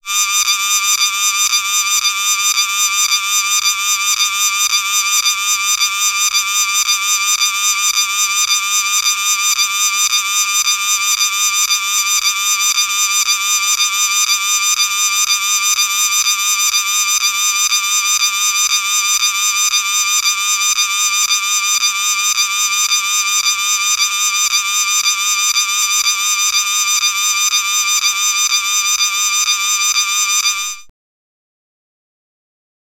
Cicadas emit species-specific tymbalizations (songs), which are available online as supporting material to this volume (111 downloadable audio tracks).
Cicada tymbalization downloads